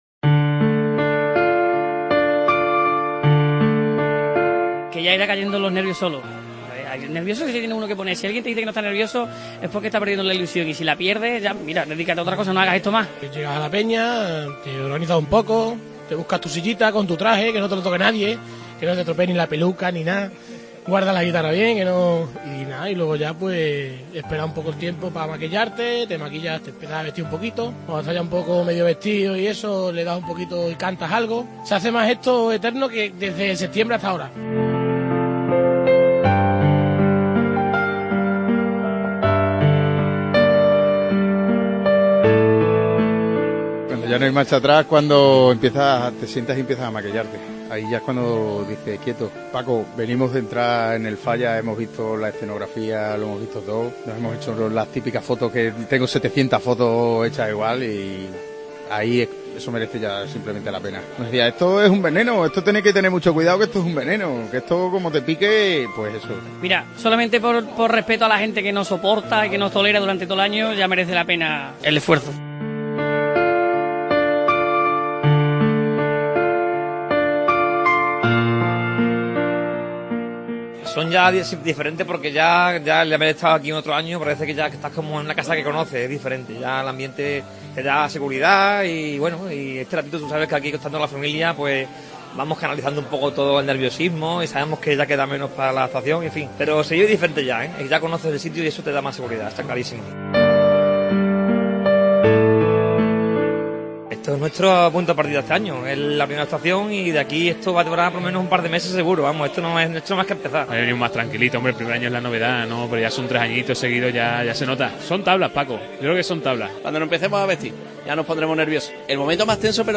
REPORTAJE CHIRIGOTA "QUÍTATE DEL MEDIO" EN EL FALLA